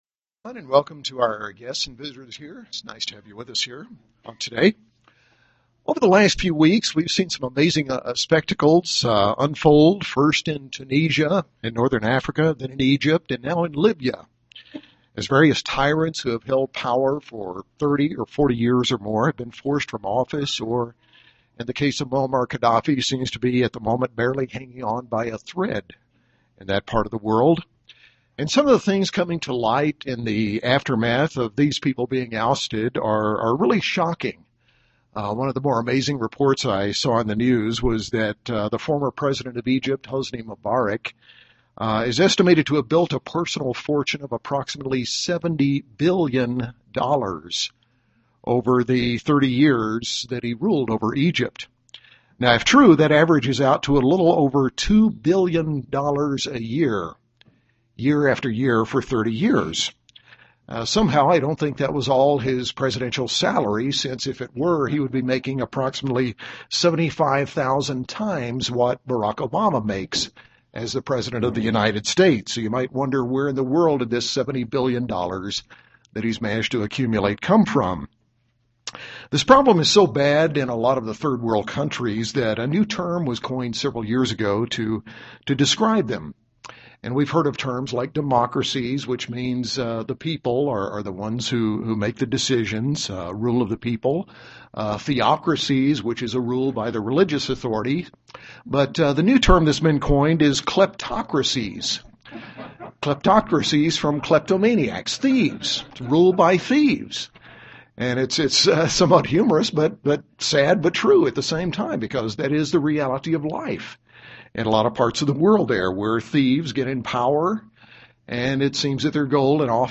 In this sermon we examine the kind of leadership Jesus Christ dose not want us to exhibit, and four key attributes of leadership that Jesus Christ wants to see in all of His followers--a serving attitude, showing care for others, loving others and humility.